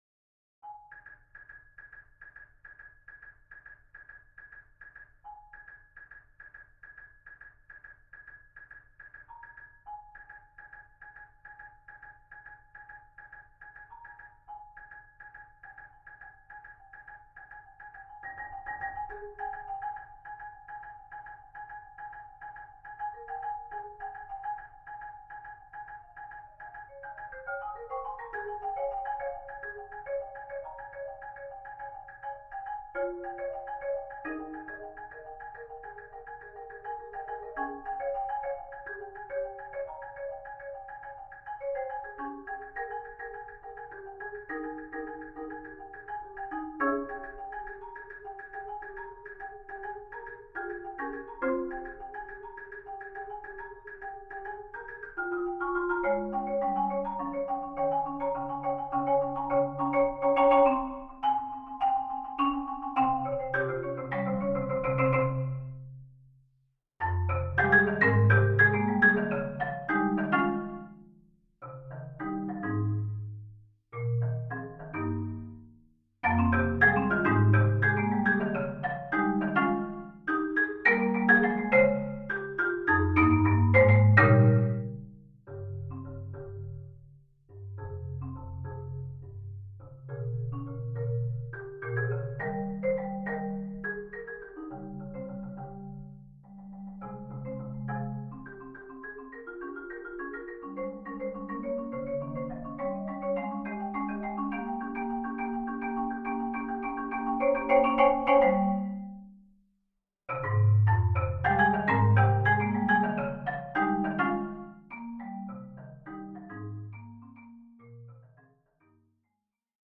Voicing: Marimba Quartet